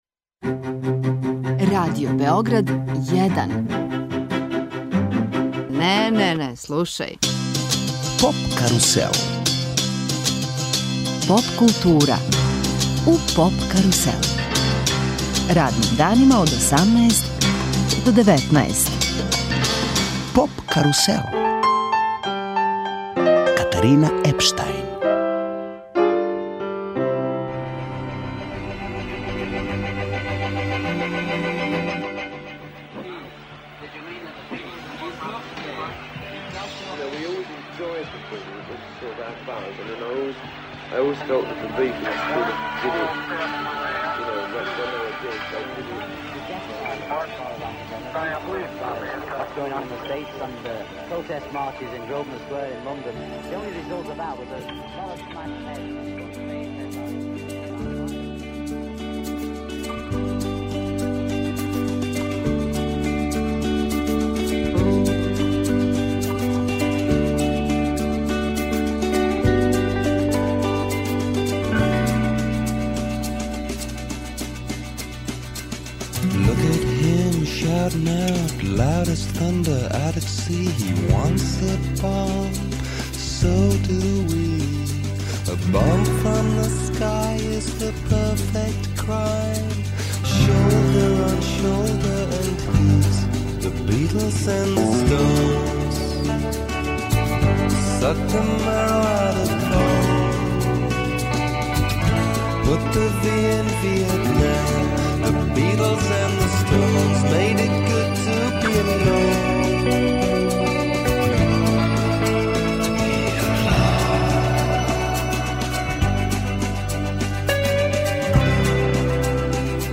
EXIT и Радио Београд 1, у оквиру програма CEETEP: гости емисије биће чланови састава DreDDup.
Музику DreDDup бенда карактеришу мрачни звуци пропраћени оштрим ритмовима који су обојени метализираним вокалом. Бенд је највише под утицајем пост'индустријал сцене осамдесетих и средине деведесетих.